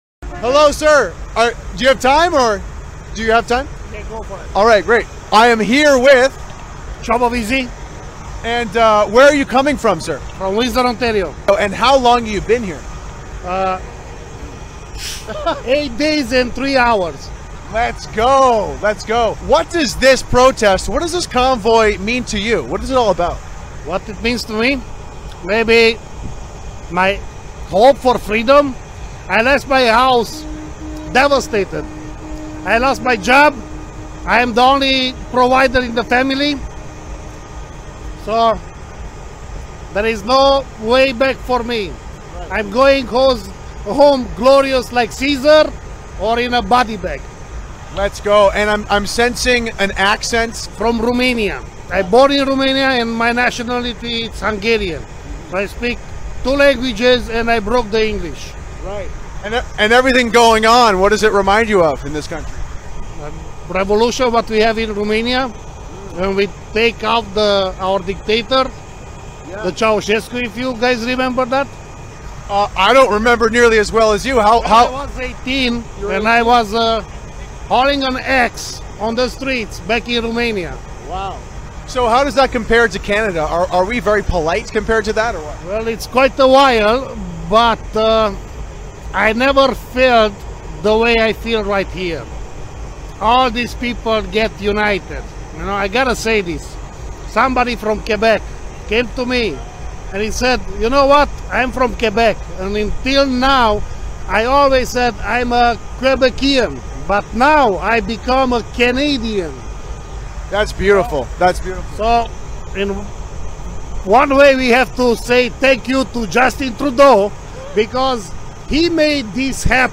ראיון עם נהג משאית בקנדה